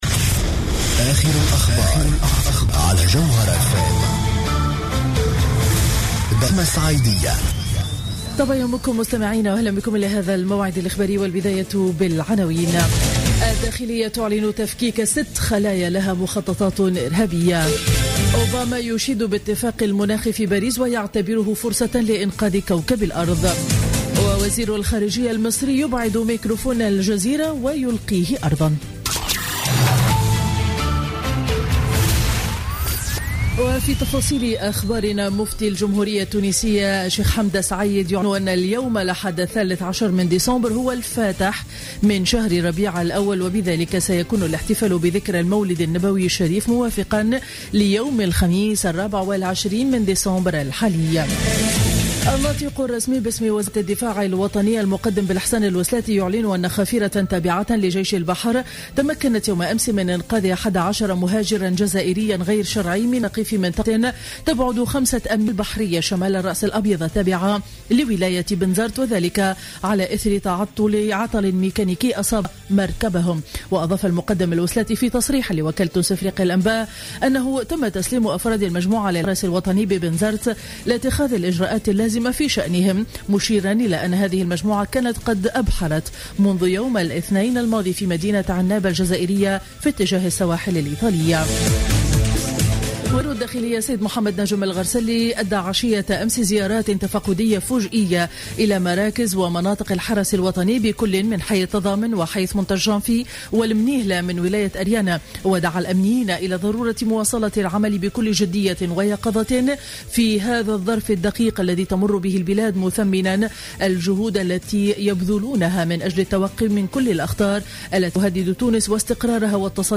نشرة أخبار السابعة صباحا ليوم الأحد 13 ديسمبر 2015